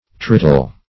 trityl - definition of trityl - synonyms, pronunciation, spelling from Free Dictionary Search Result for " trityl" : The Collaborative International Dictionary of English v.0.48: Trityl \Tri"tyl\, n. [Gr.